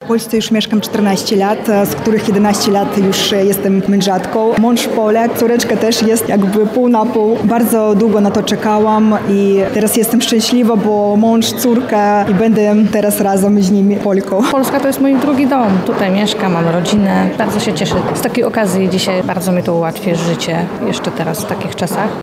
– To jest bardzo ważna chwila dla nas – mówią osoby, które odebrały dziś akty nadania obywatelstwa polskiego.